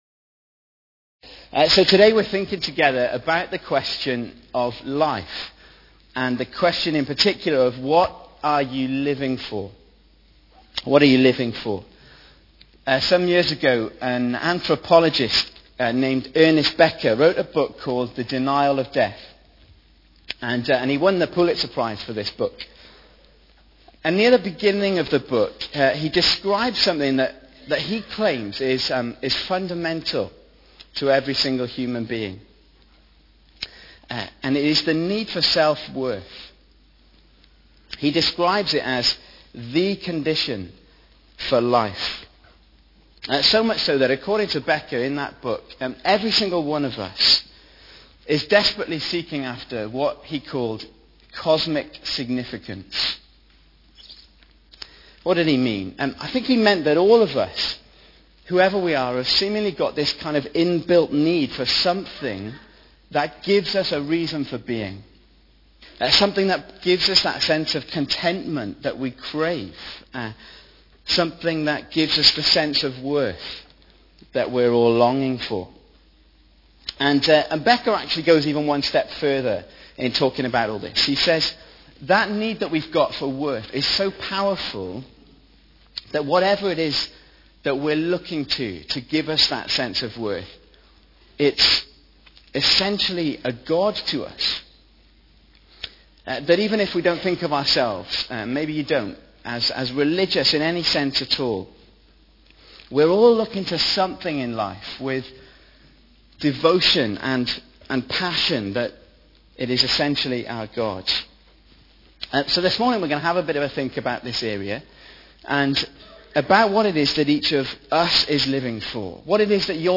2012 Service type: Sunday AM Bible Text